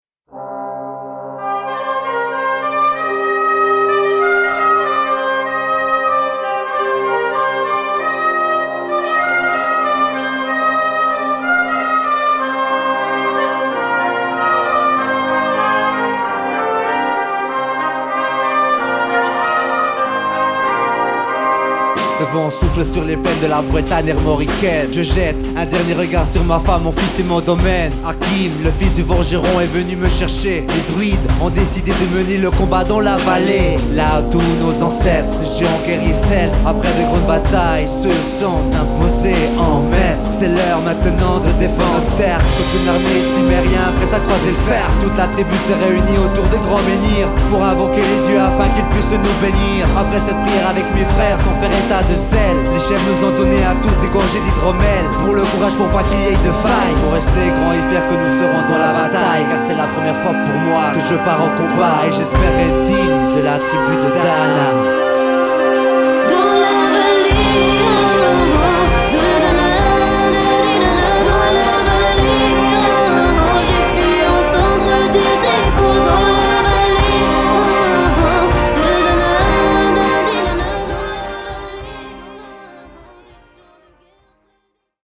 Gattung: Blasorchester mit Solo Gesang
Besetzung: Blasorchester
Ein Stück für Rap-Sänger und Blasorchester.